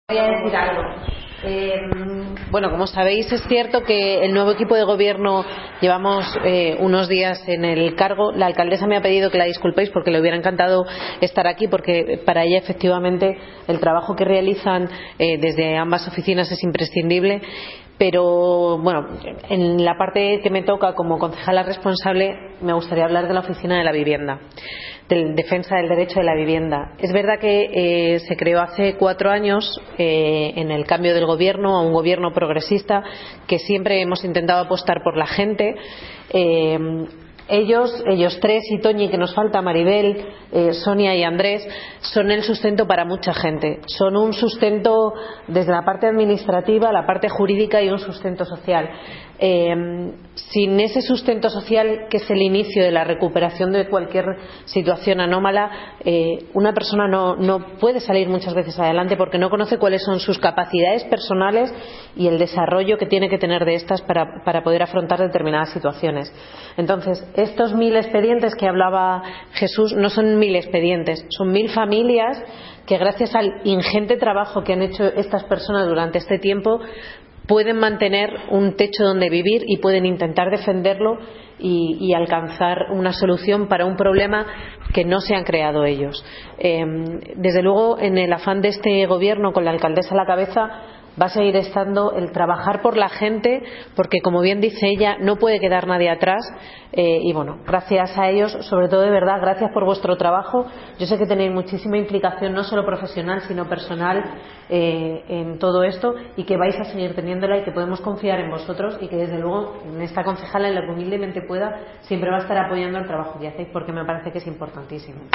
Audio - Maria Luisa Ruiz (Concejala de Desarrollo Urbanismo) Sobre Reconocimiento AAVV El Recreo